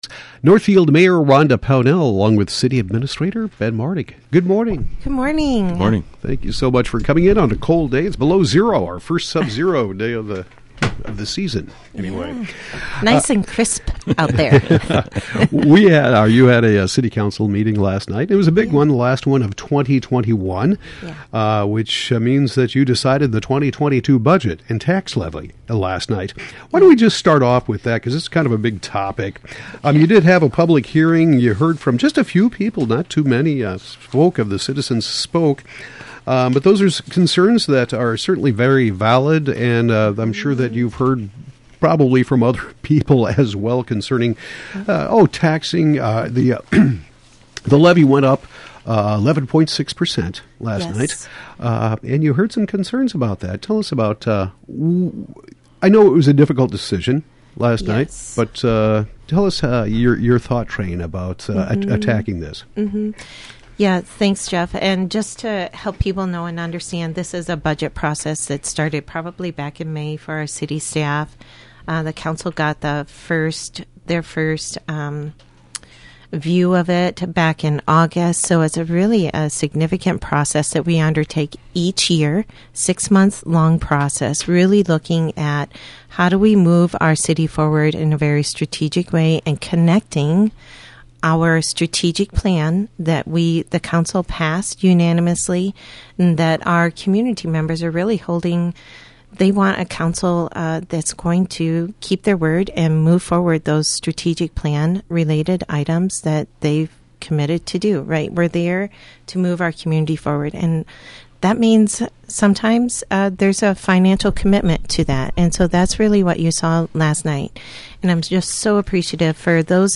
Northfield Mayor Rhonda Pownell and City Administrator Ben Martig discuss the December 7 City Council meeting. Topics include the budget and tax levy.